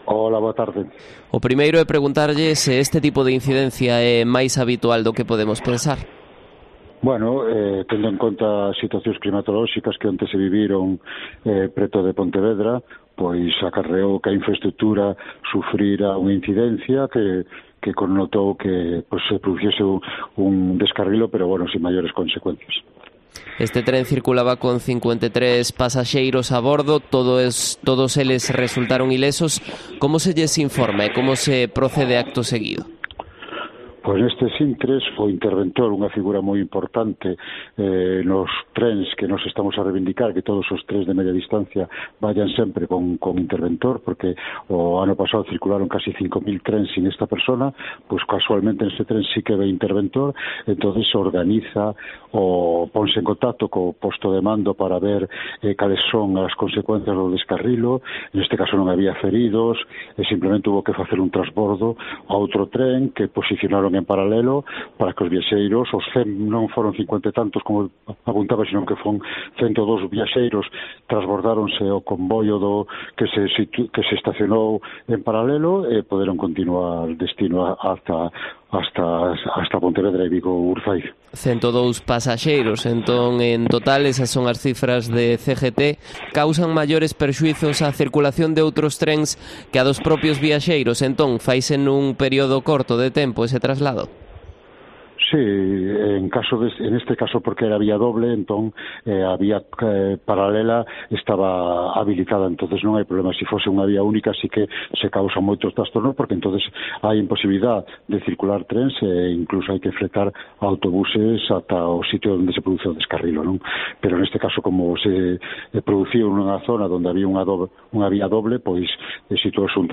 AUDIO: Entrevista
Madrid - Publicado el 03 dic 2020, 16:44 - Actualizado 17 mar 2023, 09:42